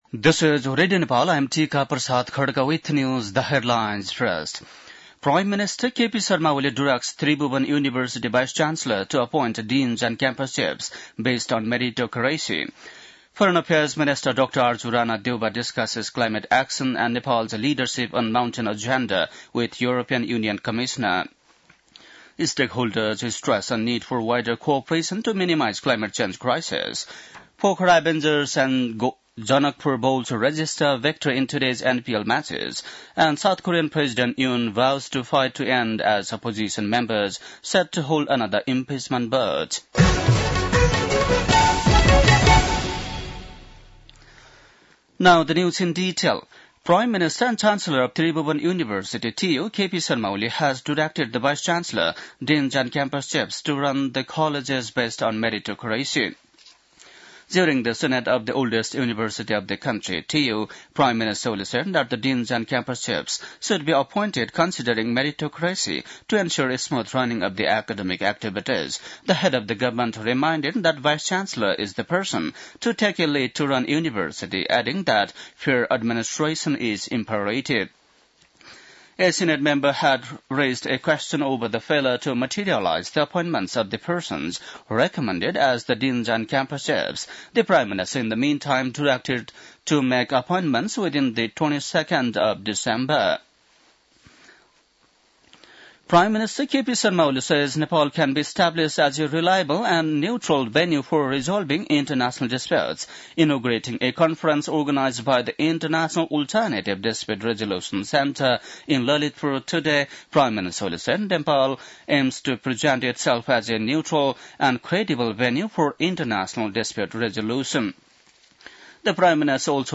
बेलुकी ८ बजेको अङ्ग्रेजी समाचार : २८ मंसिर , २०८१
8-PM-English-NEWS.mp3